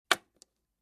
StoneSound4.mp3